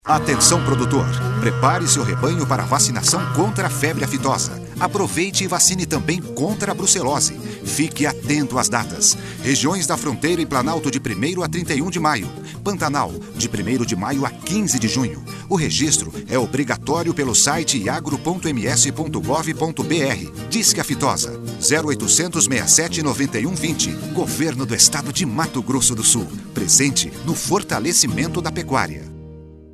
Spot para rádio